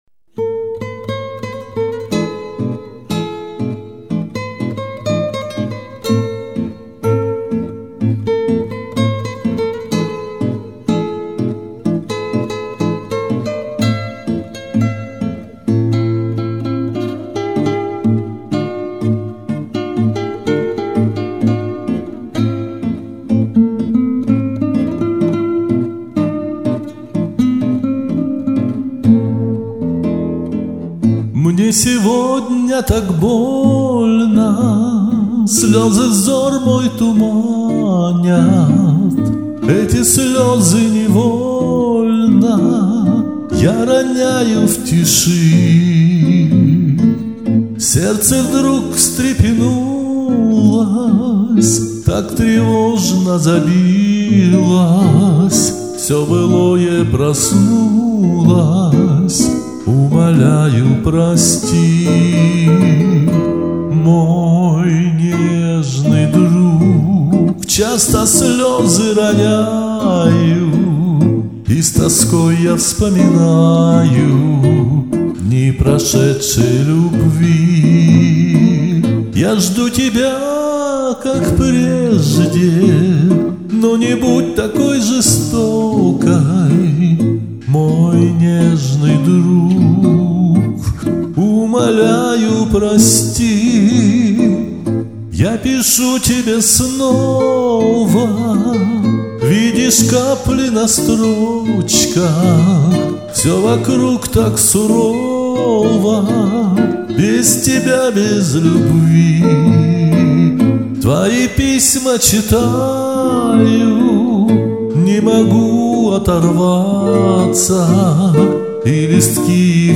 слева хорошие данные, но песня звучит как рыба